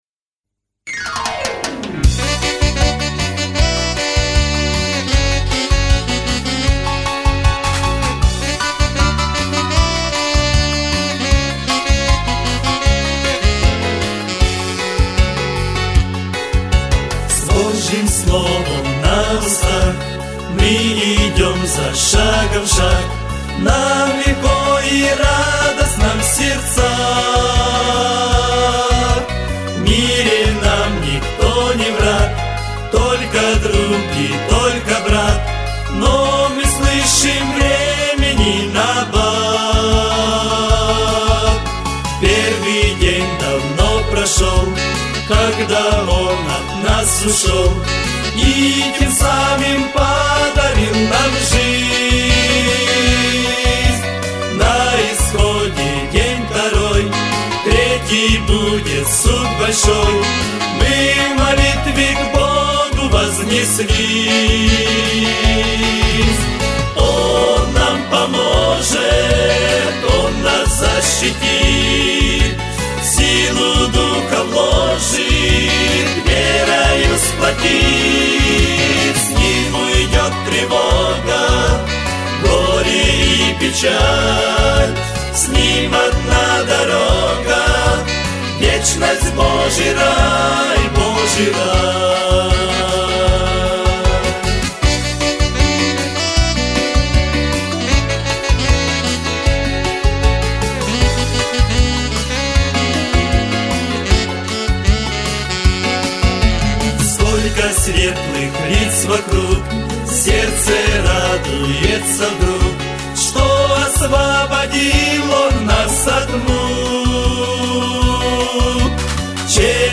529 просмотров 810 прослушиваний 92 скачивания BPM: 87